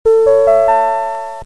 Perso je n'ai que celui qu'on entend dans les autoscooters.